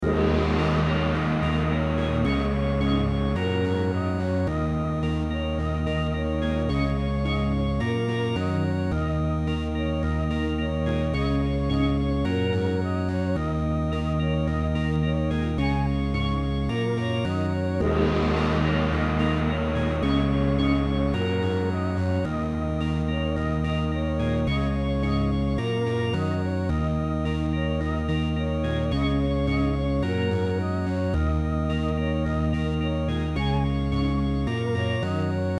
Нажмите для раскрытия... специально для вас сбаунсил петлю под которую импровизирую, прости господи, на скрипке страдивари из контакта... CS-80, орган B3, Vox Continental и мелотрон всё артуриевское Вложения 1.mp3 1.mp3 1,1 MB · Просмотры: 759